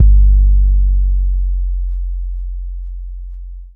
the best 808.wav